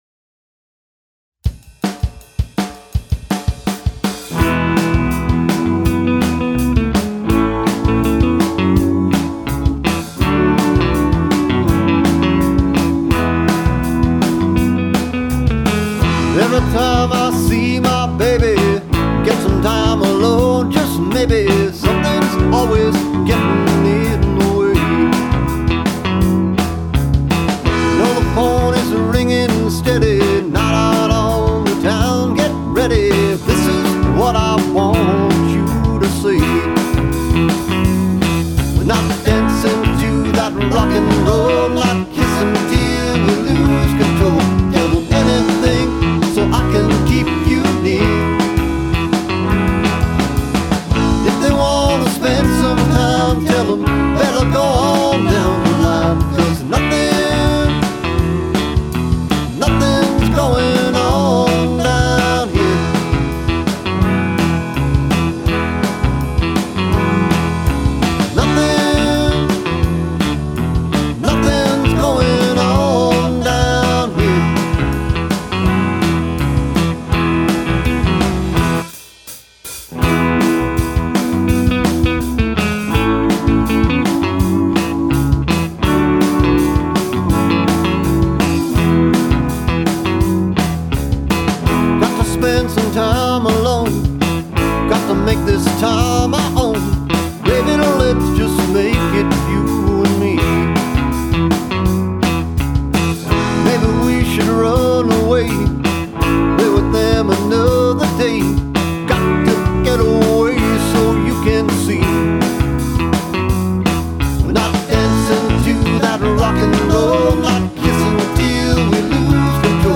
Hey - redid the mix on this starting all over mixing without anything on the mains and used a bit more eq on some channels (something I've been working on lately). Only after I had what I think is a fairly good mix, did I put it through a comp/limiter for at the end for some tone and to get the volume up.